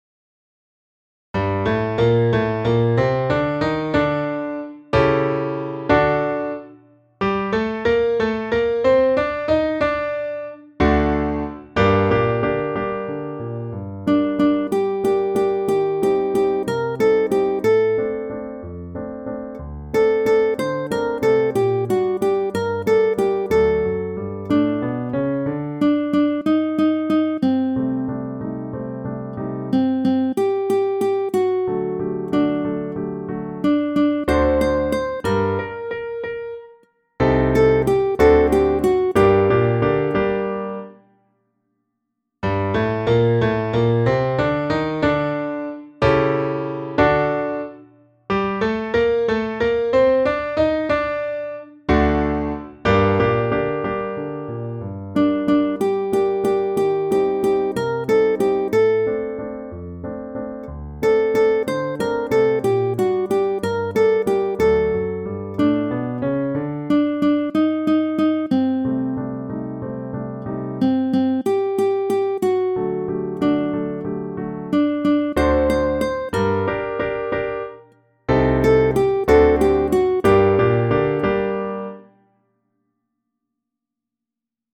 - Présentation de cette chanson : Noël, dédicacé à J.REBOUL.